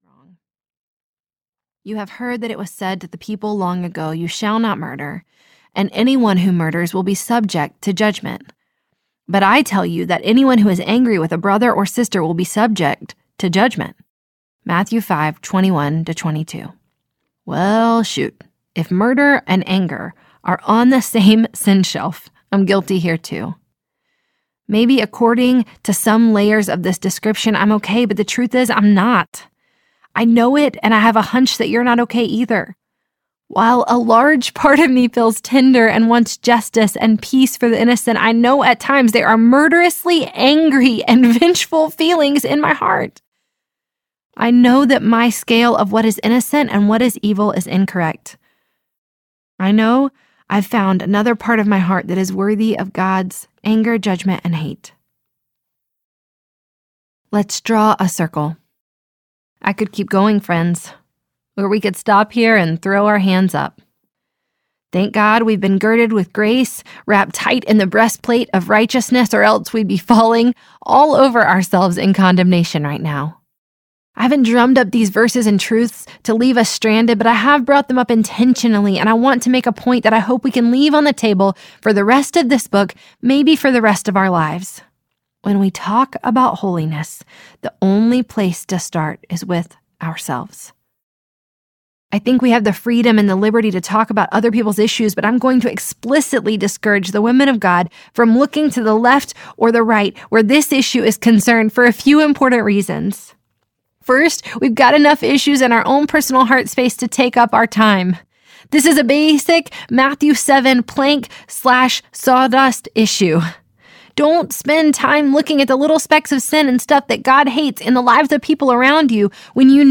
Dance, Stand, Run Audiobook